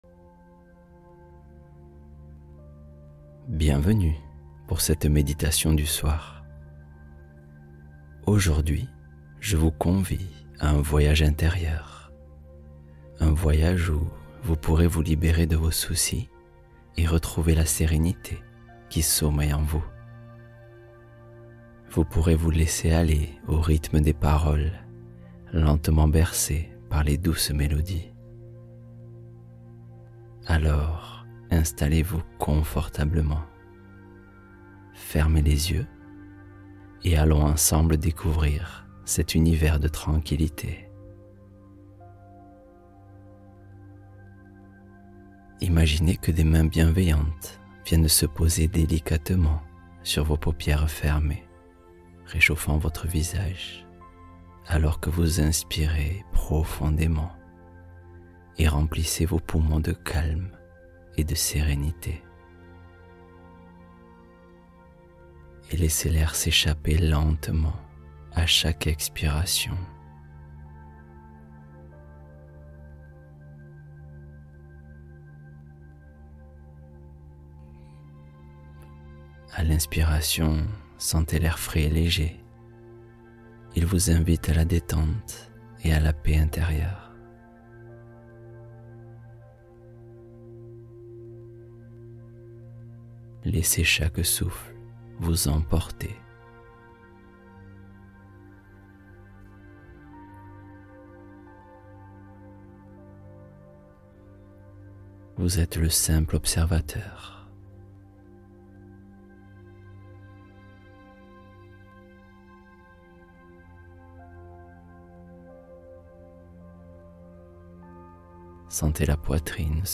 Histoire nocturne relaxante — S’endormir sans effort mental